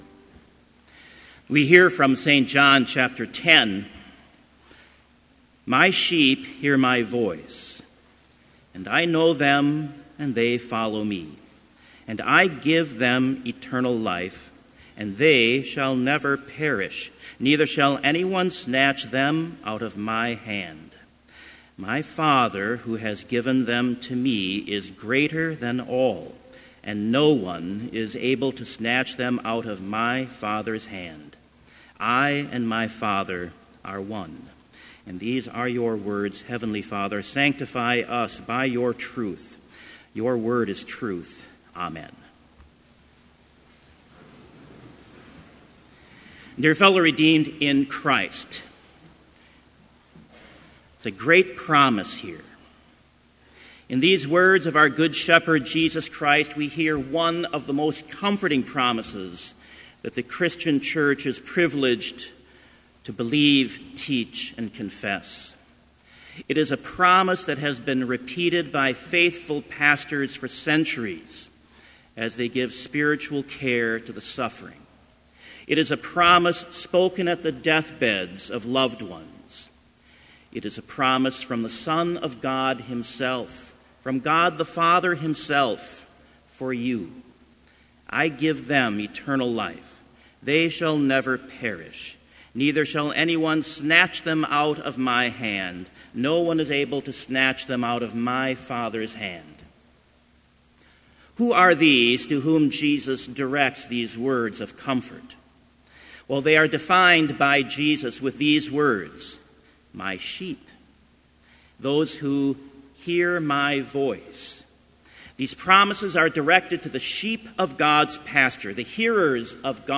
Sermon Only
This Chapel Service was held in Trinity Chapel at Bethany Lutheran College on Tuesday, April 17, 2018, at 10 a.m. Page and hymn numbers are from the Evangelical Lutheran Hymnary.